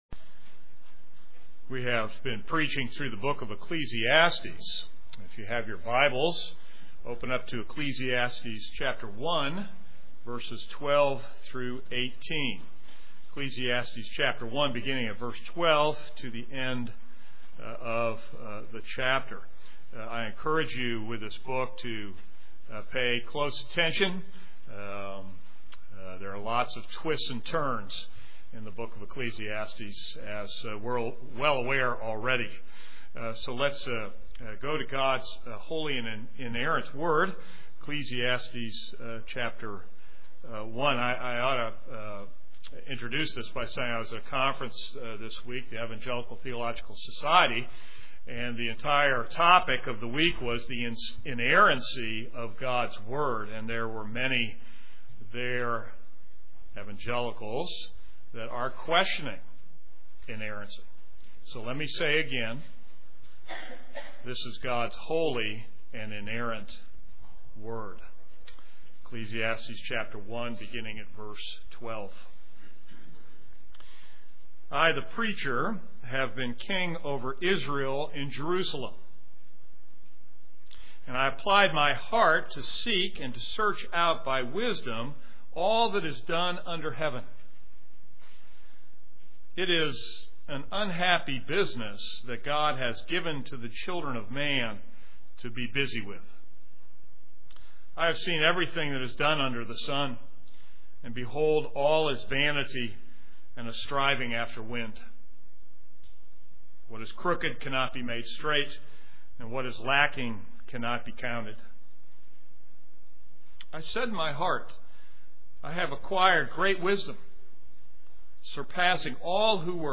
This is a sermon on Ecclesiastes 1:12-18.